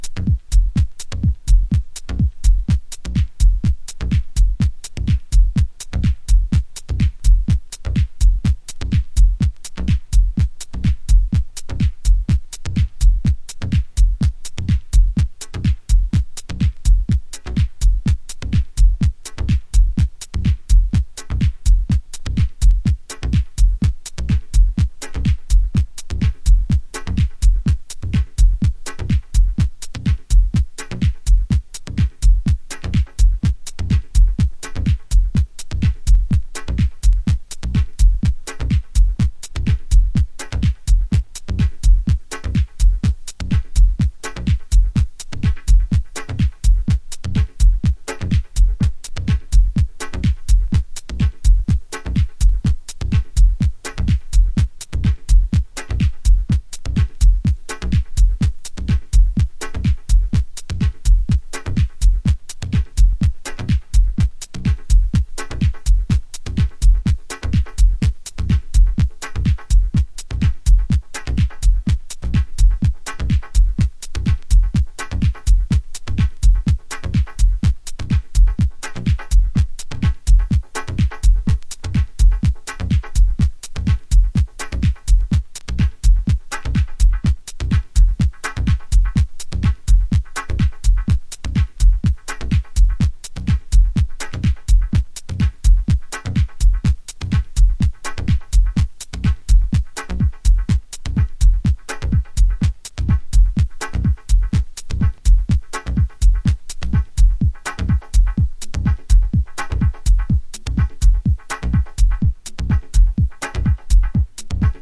Early House / 90's Techno
Dub